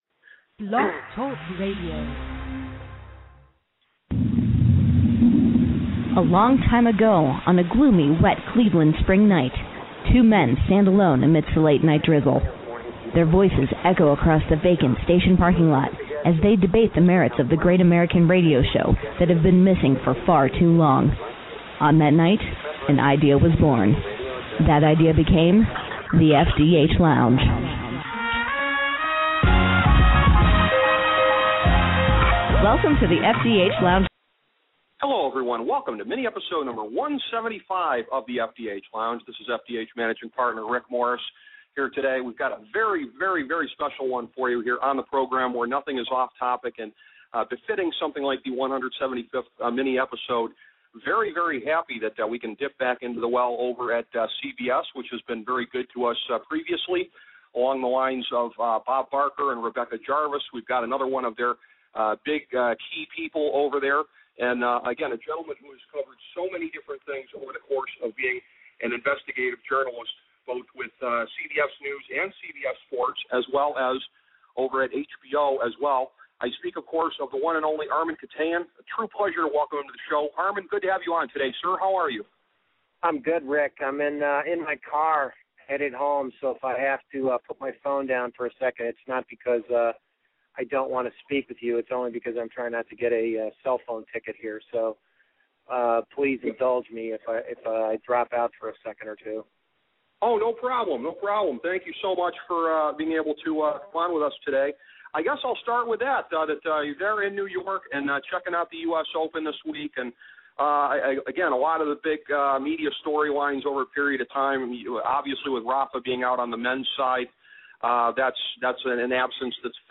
A conversation with CBS & HBO journalist Armen Keteyian